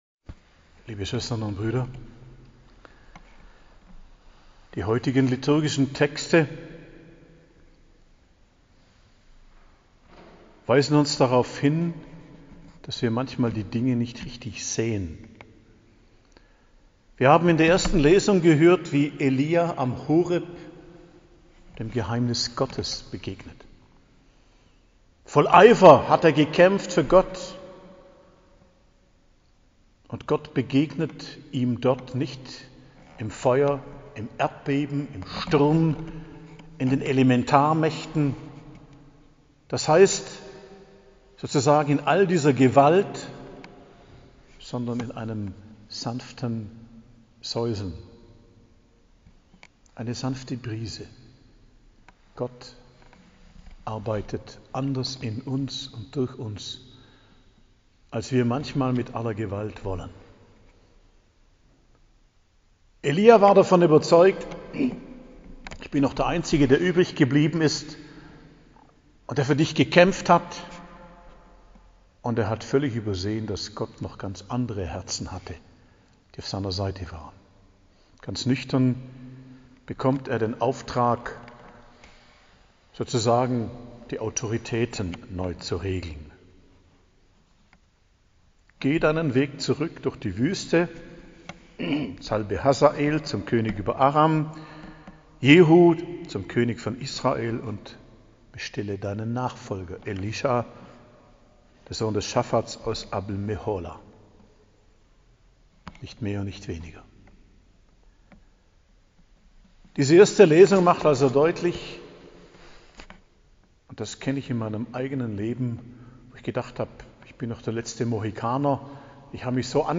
Predigt am Freitag der 10. Woche i.J., 10.06.2022